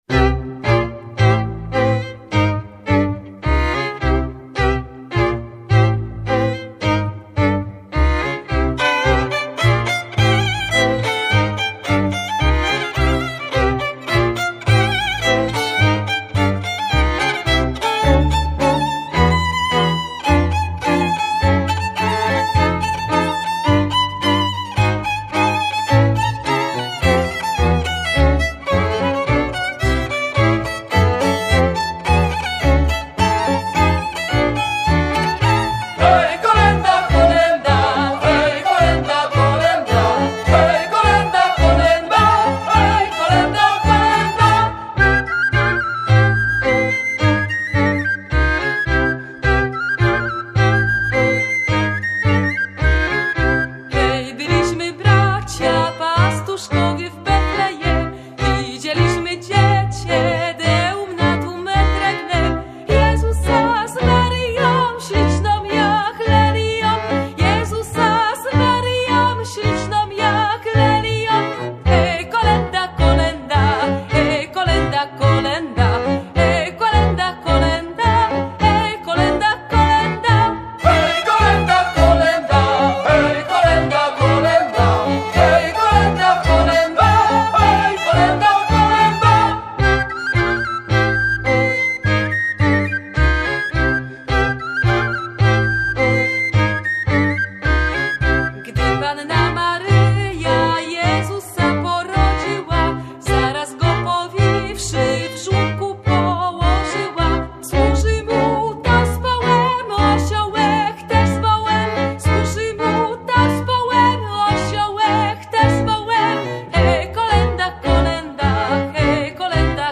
instrumenty pasterskie
- kolęda (3,3 Mb)